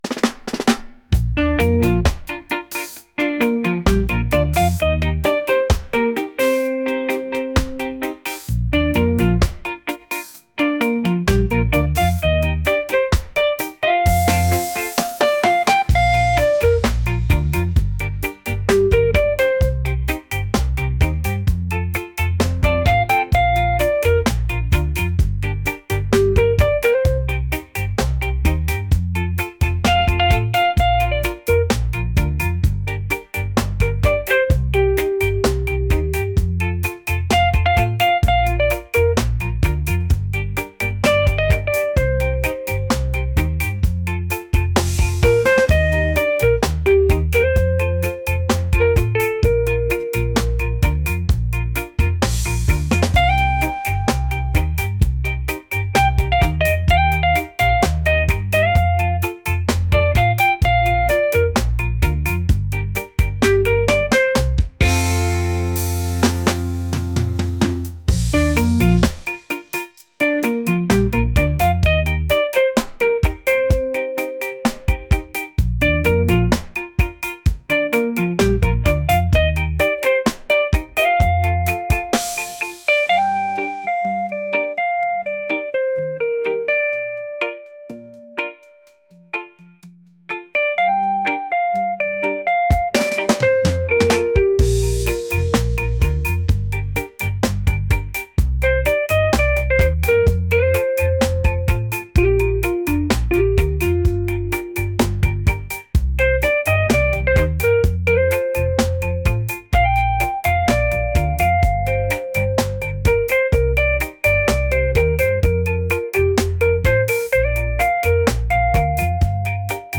reggae | fusion | laid-back